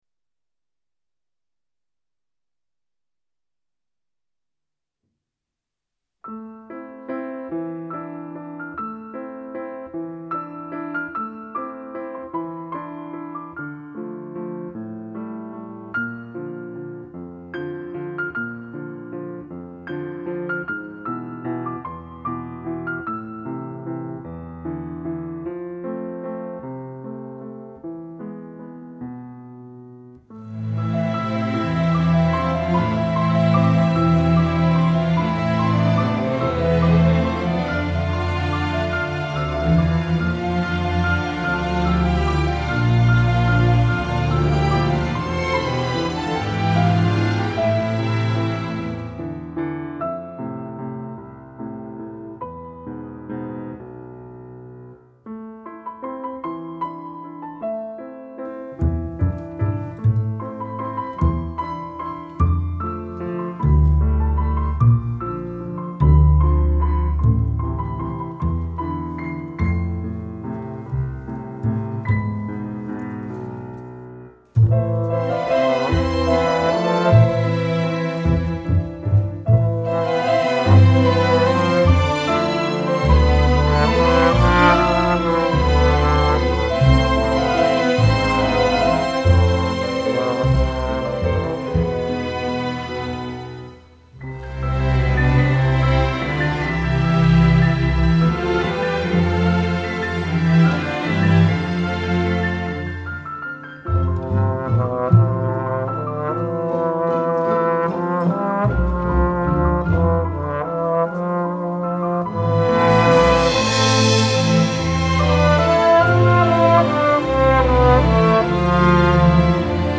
Ορχηστρική μουσική